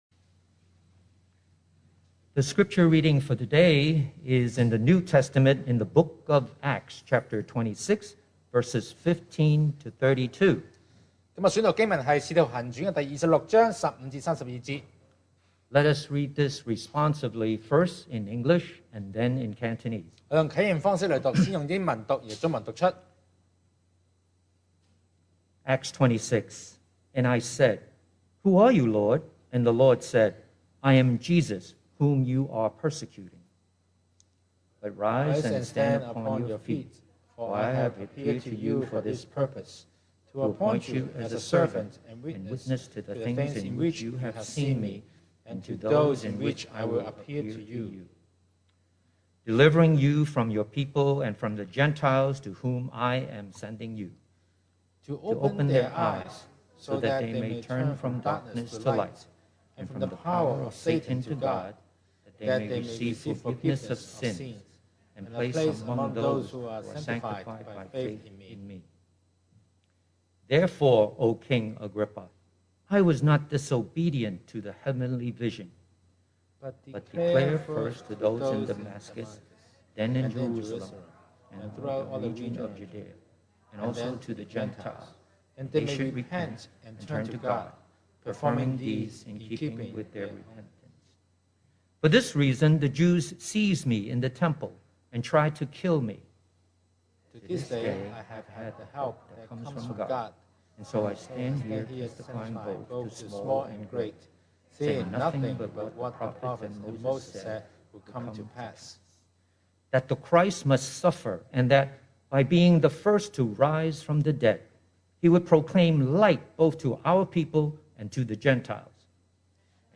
2022 sermon audios
Service Type: Sunday Morning